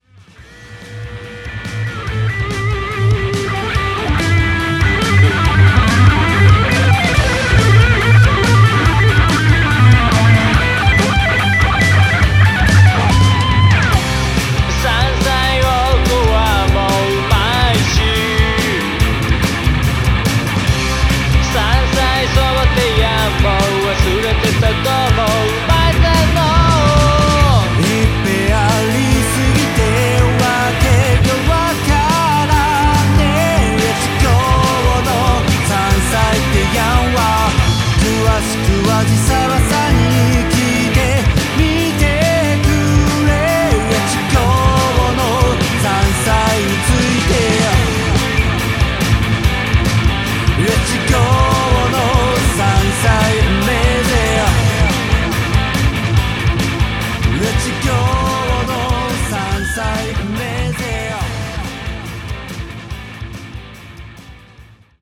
ロックバンド
ちょっぴりいねよな土着ロック 聴いてみませんか？